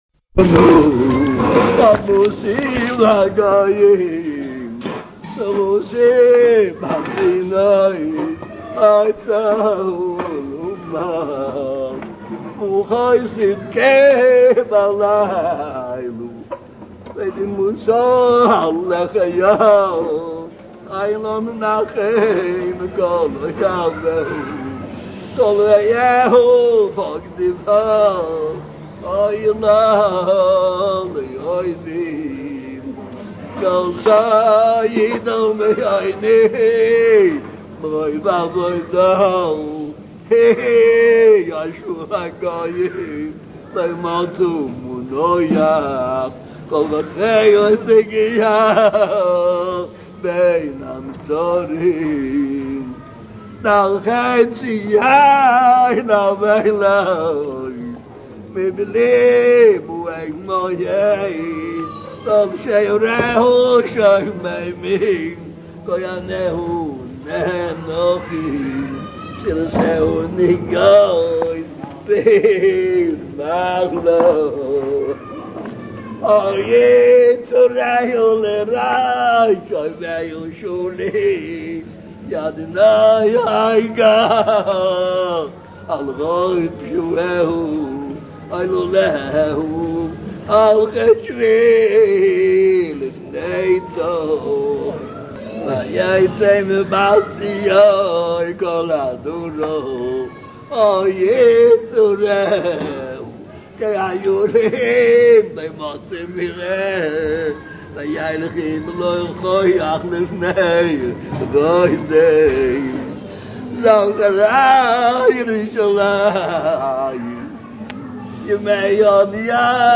הקלטה חיה
בקריאת מגילת איכה בבית הכנסת הגדול של חסידי ברסלב בירושלים.
האזינו – ותשמעו איך עובד השם אמיתי מתאבל על החורבן
מגילת-איכה-בציבור.mp3